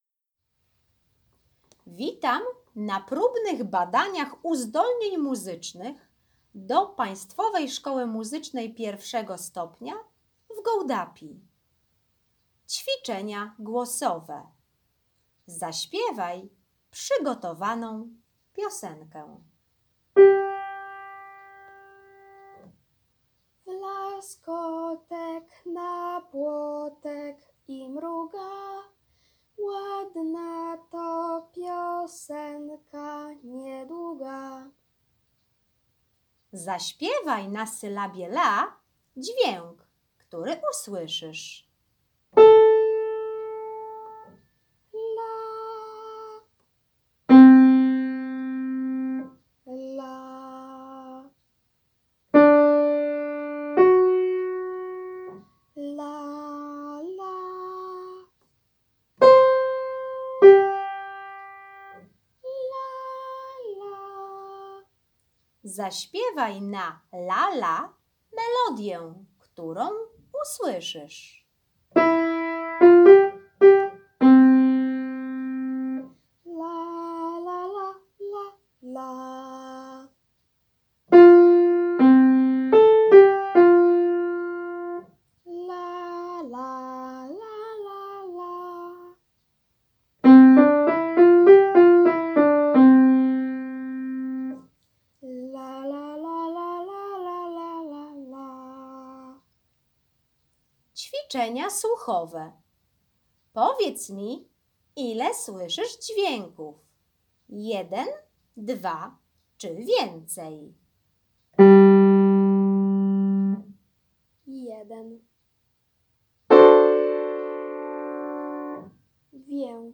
przykładowy test badania predyspozycji słuchowych
test_sluchowy.mp3 5.35MB Test słuchowy - transkrypcja